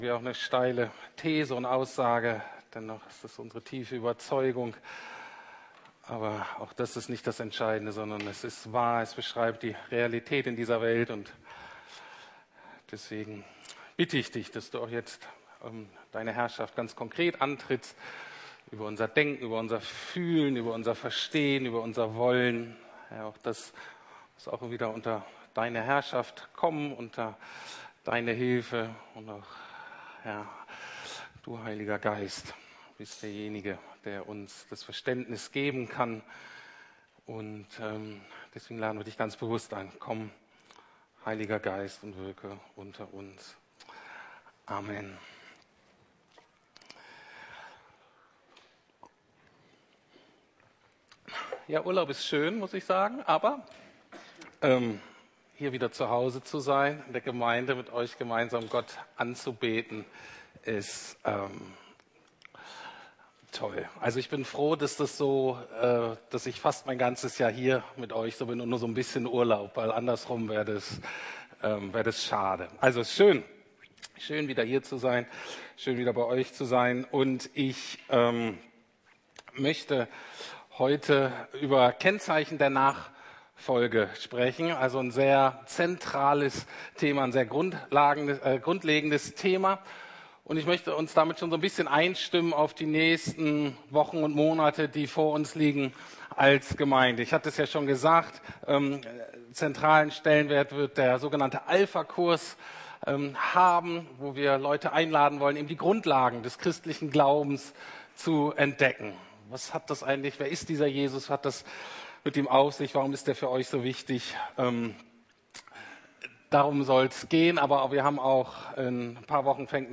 Kennzeichen der Nachfolge ~ Predigten der LUKAS GEMEINDE Podcast